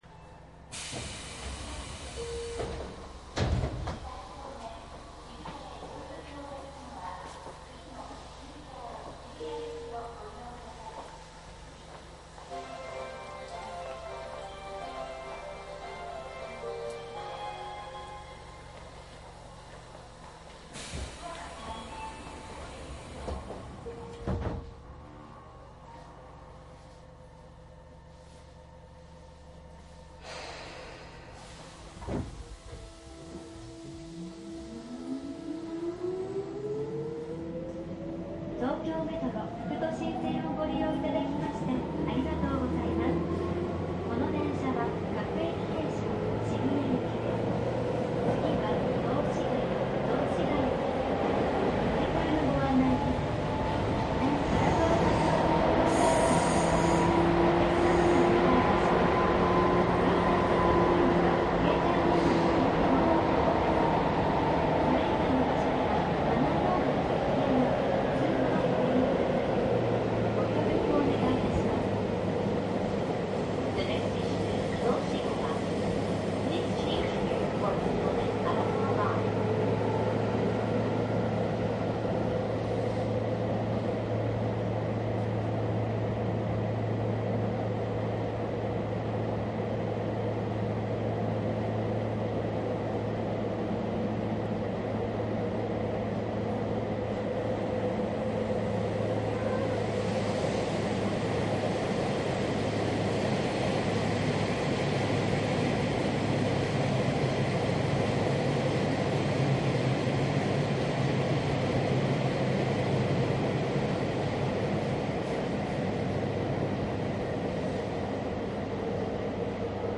東武9000系   副都心線 走行音CD
内容は副都心線渋谷暫定開業時にて録音したものです。
いずれもマイクECM959です。DATかMDの通常SPモードで録音。
実際に乗客が居る車内で録音しています。貸切ではありませんので乗客の会話やが全くないわけではありません。